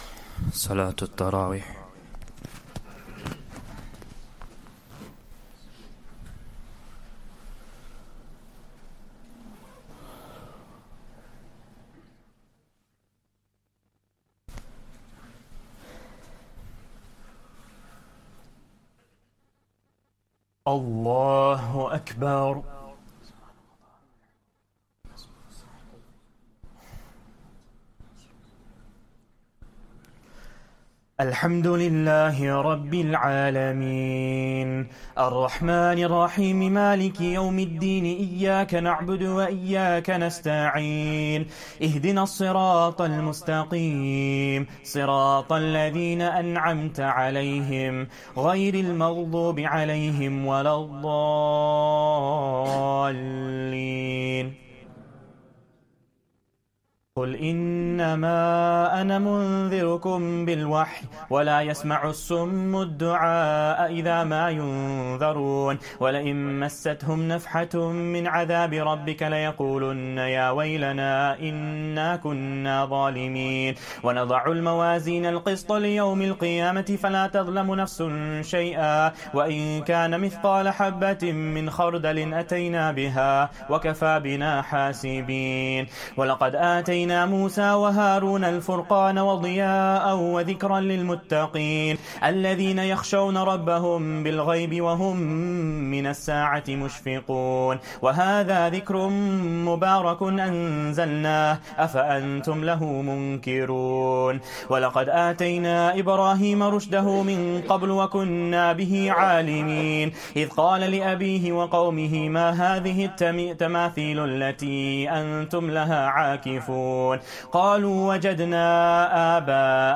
Taraweeh Prayer 14th Ramadan